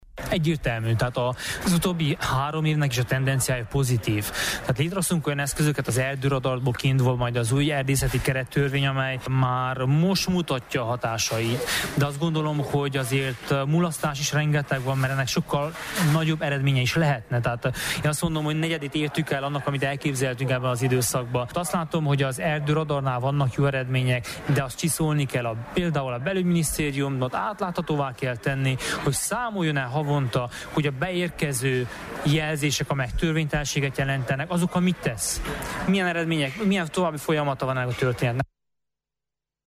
Erdő nélkül nincs Erdély címmel zajlott panelbeszélgetés tegnap délután a bálványosi szabadegyetemen. Korodi Attila volt környezetvédelmi minisztertől azt kérdeztük, szerinte megállítható-e az erdőpusztítás folyamata.